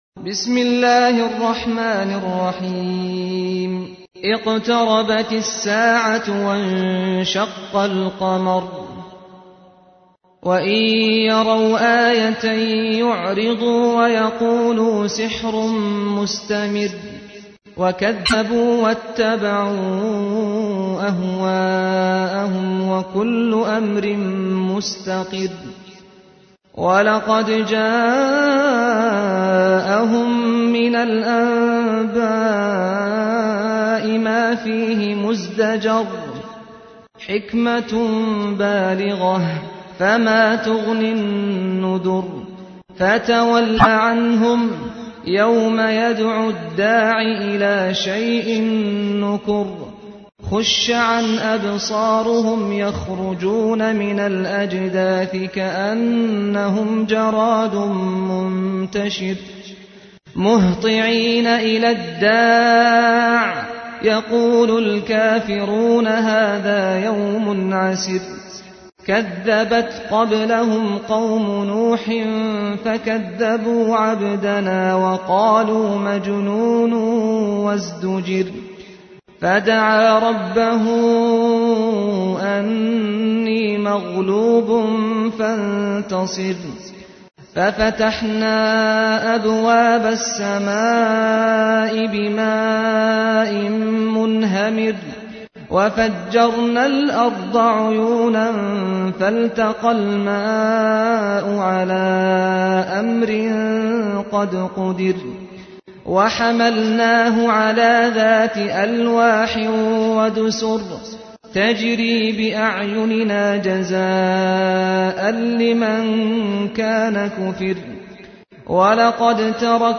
تحميل : 54. سورة القمر / القارئ سعد الغامدي / القرآن الكريم / موقع يا حسين